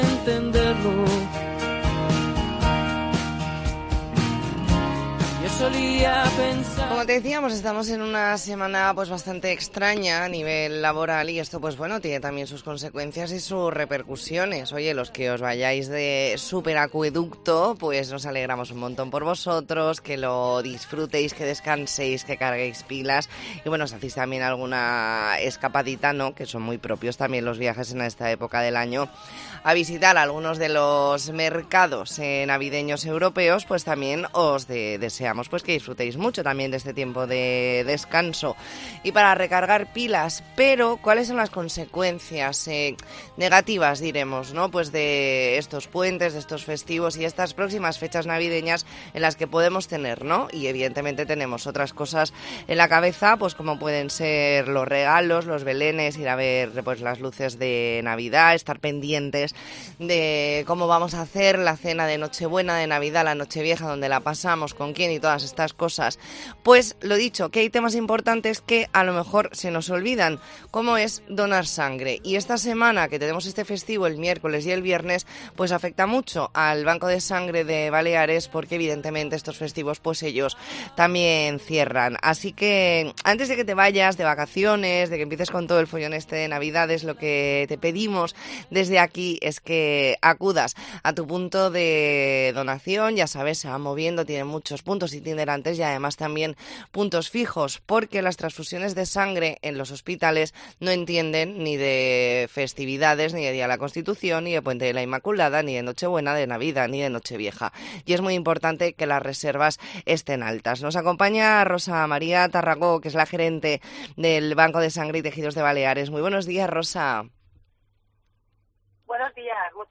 Entrevista en La Mañana en COPE Más Mallorca, martes 5 de diciembre de 2023.